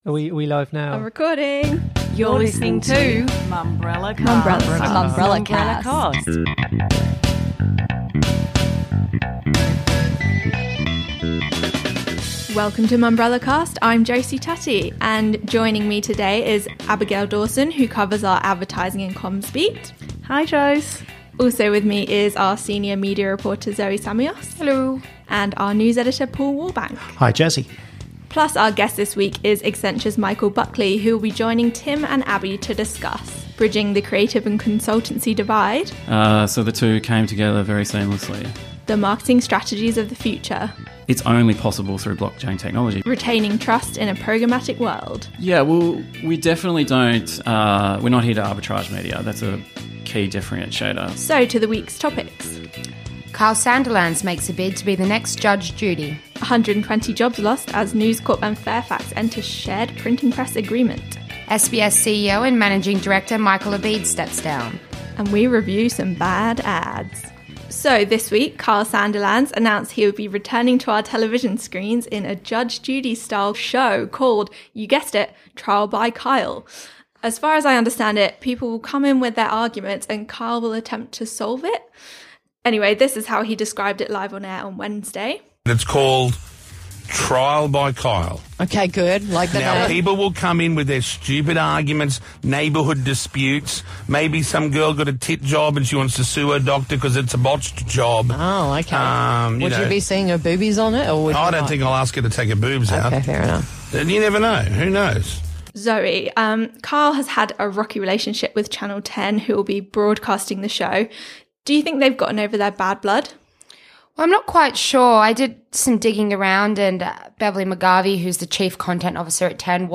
In the news